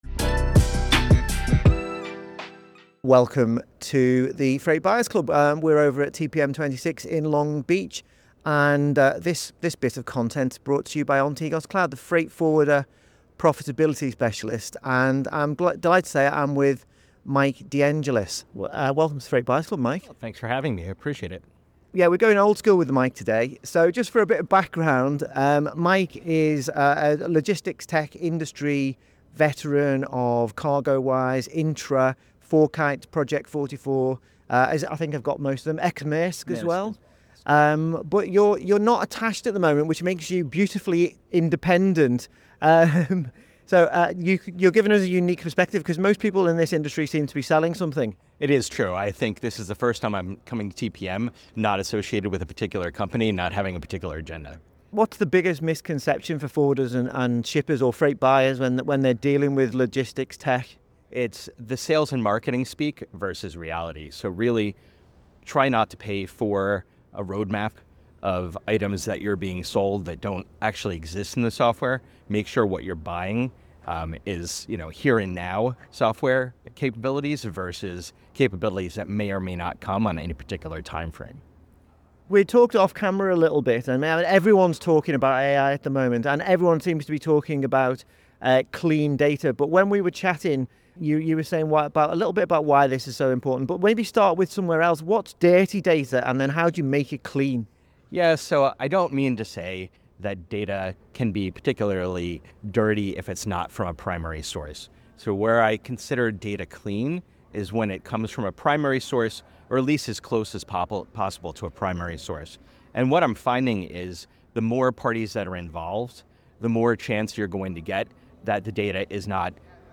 LogTech Industry veteran, ex - Maersk, INTTRA, WiseTech Global, p44, FourKites In this interview: Why the sales pitch and the software are often two differ